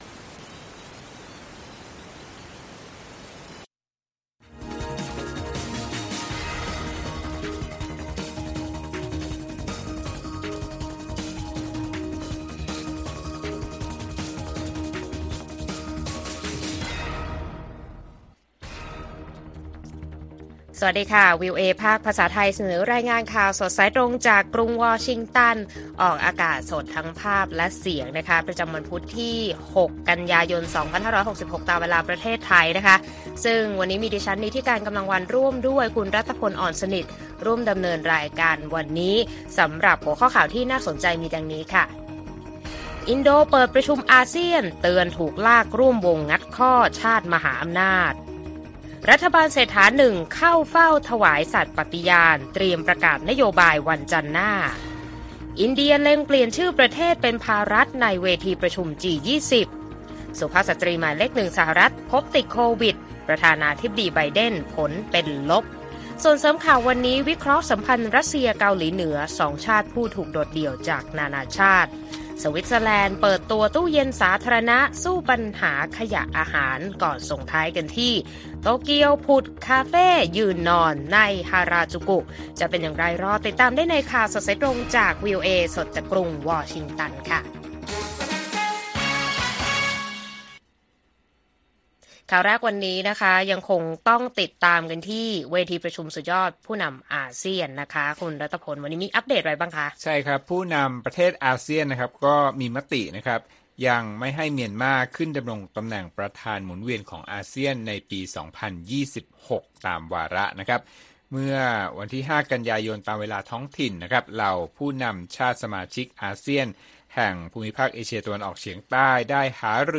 ข่าวสดสายตรงจากกรุงวอชิงตัน วัน พุธ ที่ 6 ก.ย. 2566